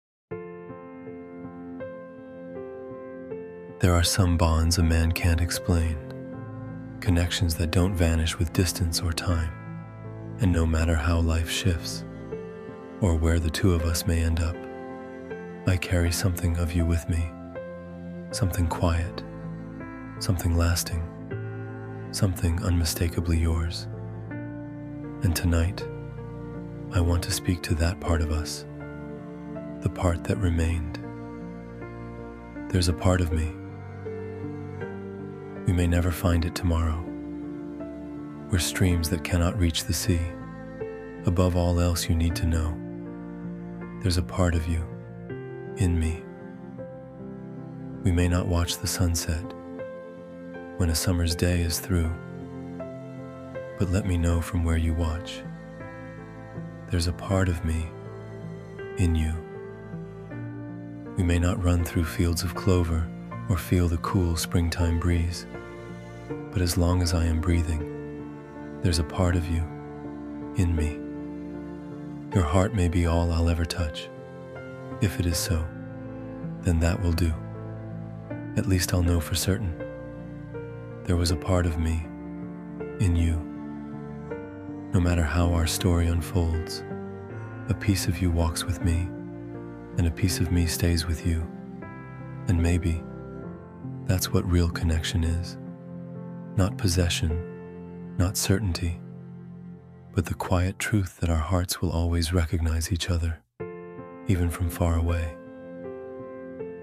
theres-a-part-of-me-romantic-poem-for-her-male-voice.mp3.mp3